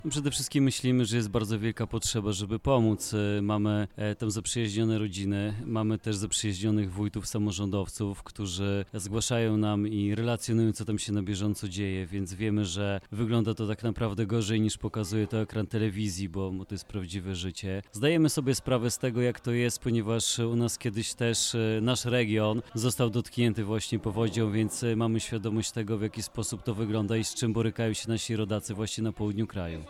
Bartosz Podolak powiedział nam, co po drugiej stronie kraju myśli się o sytuacji na Dolnym Śląsku i Opolszczyźnie.